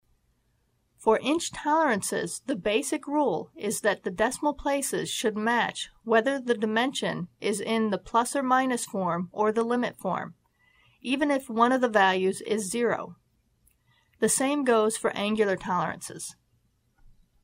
Lecture content